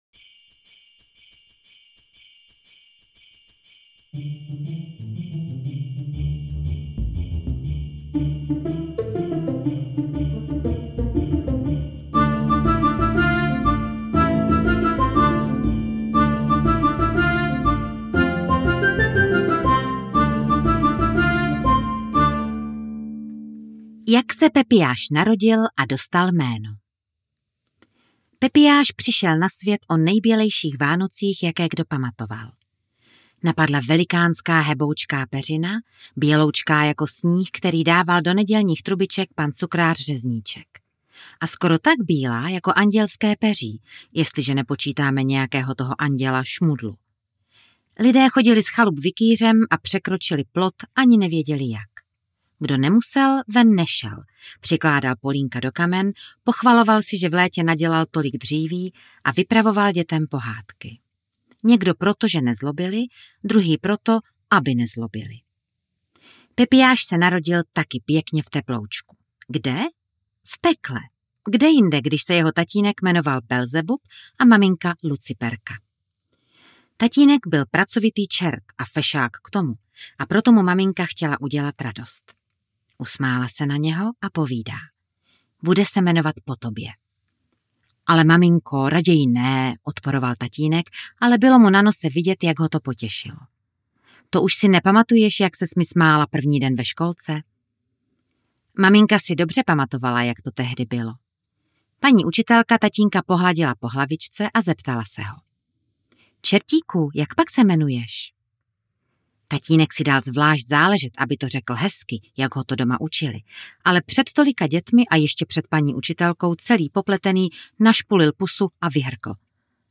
Kategorie: Dětské